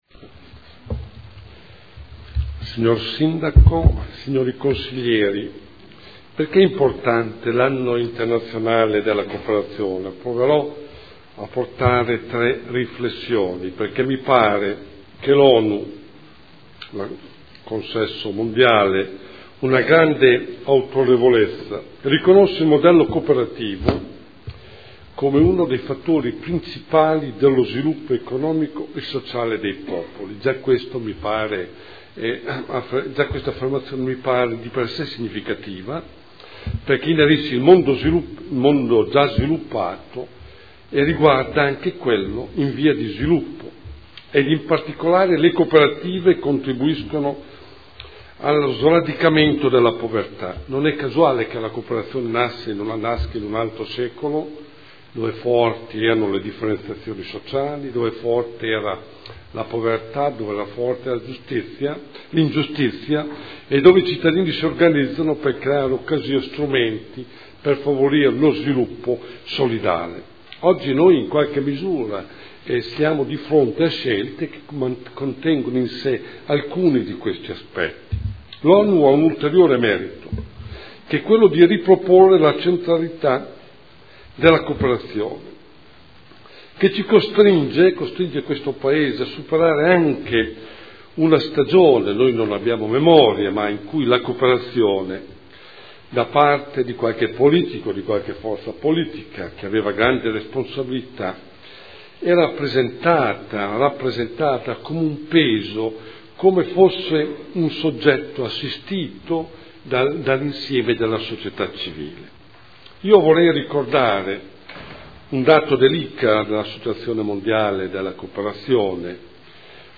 Seduta del 22/11/2012. Intervento su celebrazione dell’Anno internazionale delle cooperative indetto dall’ONU per il 2012